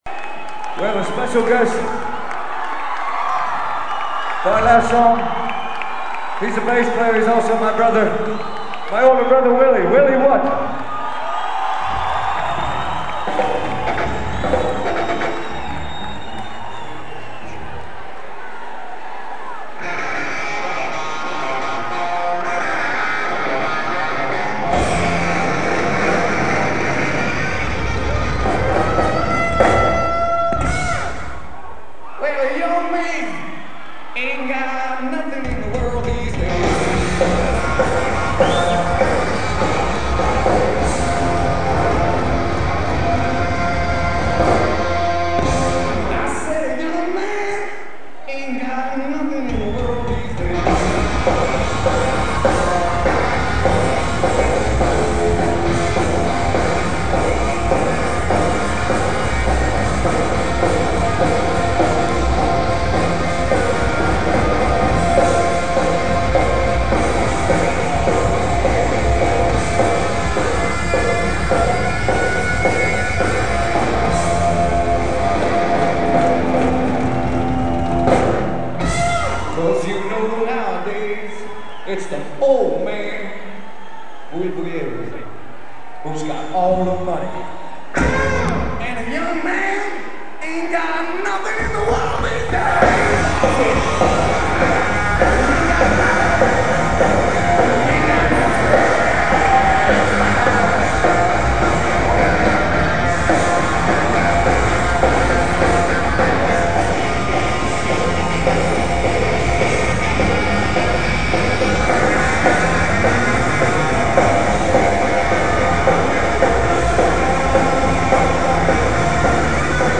live in lisbon, 24 november 1996